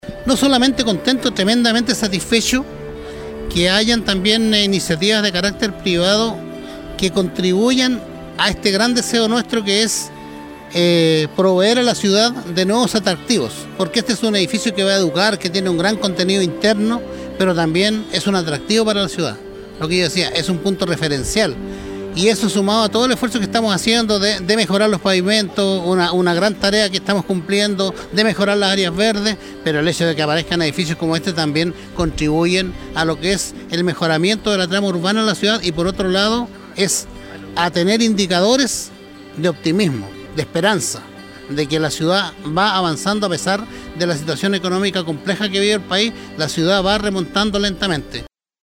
La mañana de este jueves se realizó la ceremonia oficial de la inauguración de las nuevas dependencias de la Sede Copiapó de Inacap, el cual cuenta con un edificio moderno de alta tecnología de cinco pisos.
Marcos López, alcalde de Copiapó mencionó que la inauguración de este nuevo edificio, sin duda que viene a aportar no solo al desarrollo educativo de la ciudad, sino que además viene a contribuir al crecimiento de la capital regional, transformando esta estructura en un polo de distinción e identificación con lo que es el nuevo Copiapó